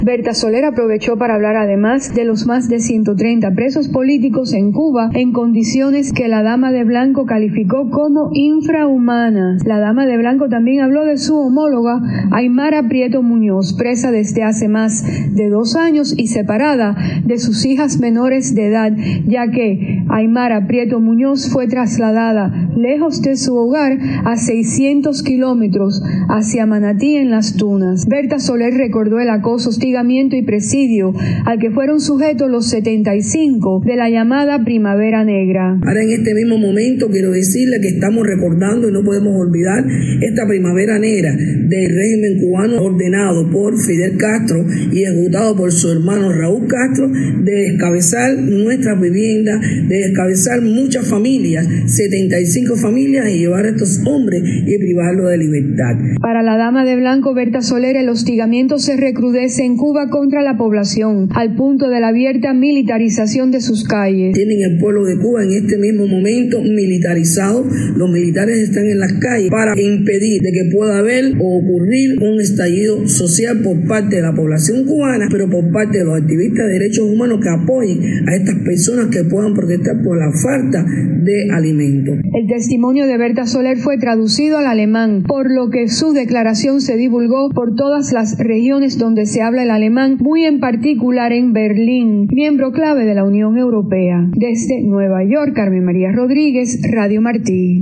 Declaraciones de Berta Soler ante la Sociedad International de Derechos Humanos